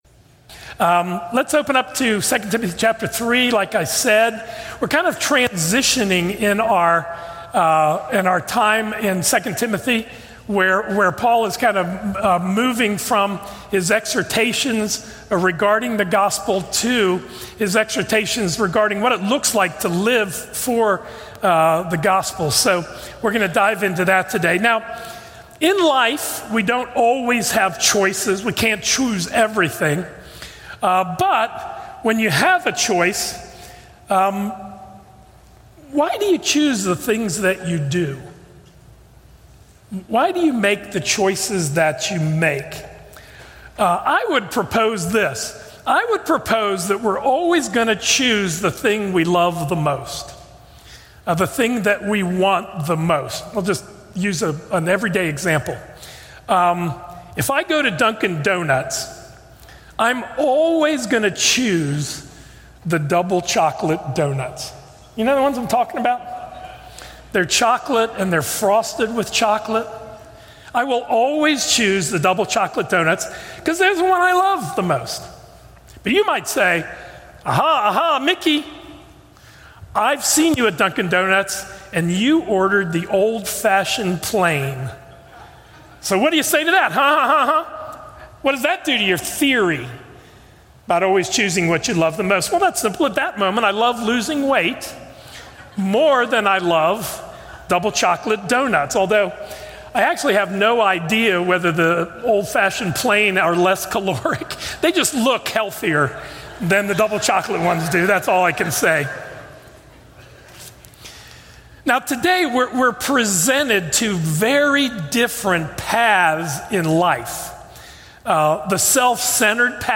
A message from the series "Behold Him."